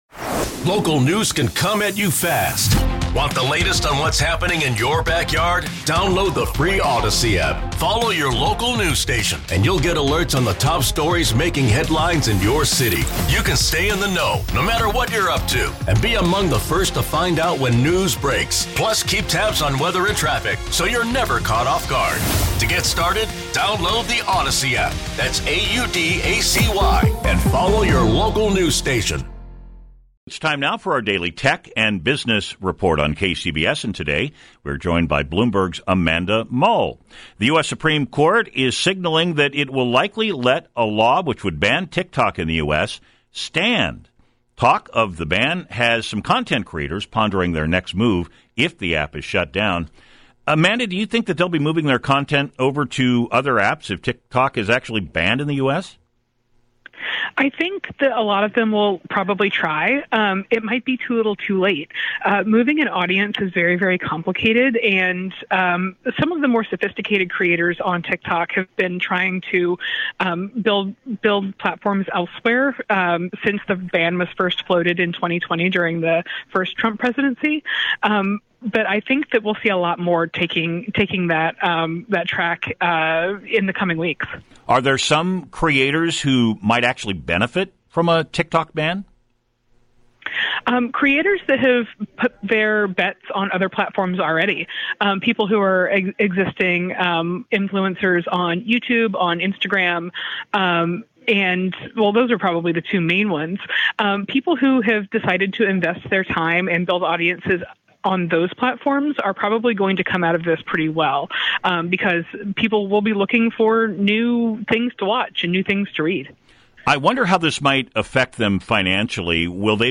This is KCBS Radio's daily Tech and Business Report.